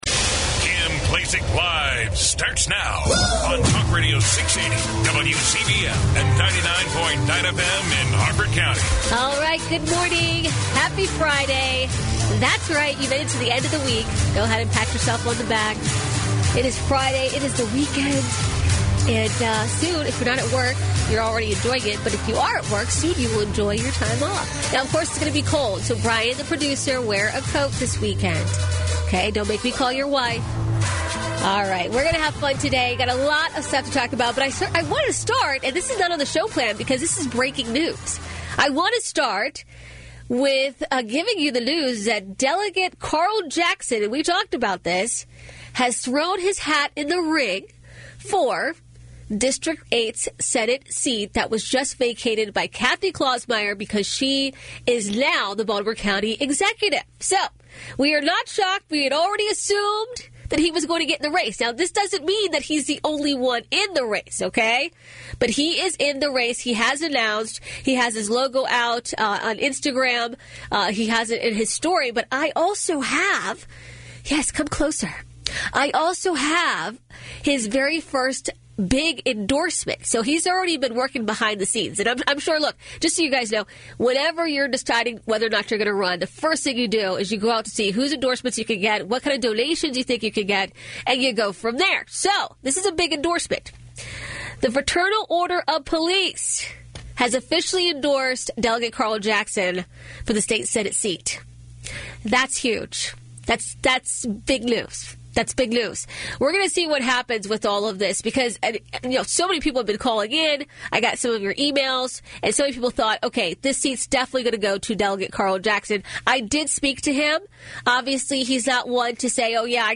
Whether you agree with her or not, you’ll always find her commentary to be thought-provoking and engaging. Don’t miss your chance to hear from the one and only Kim Klacik live on WCBM weekdays from 9am to noon.